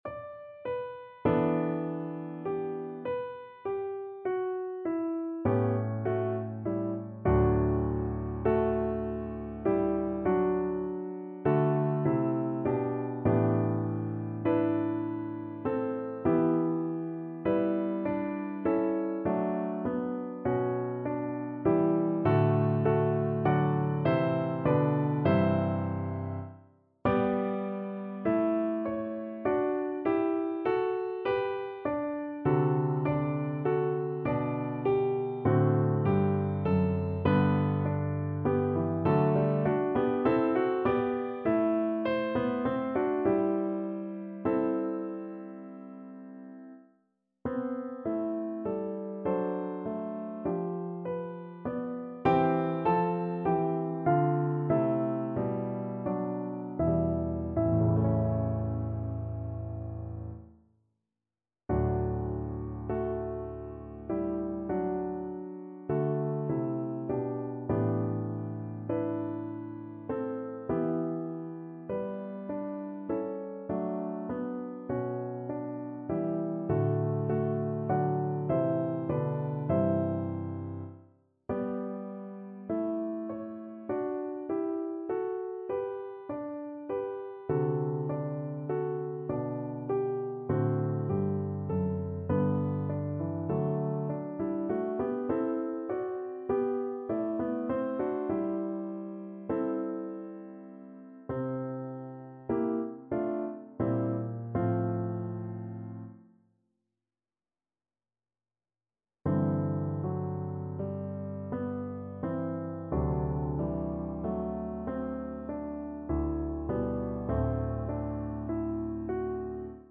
5/4 (View more 5/4 Music)
Classical (View more Classical Cello Music)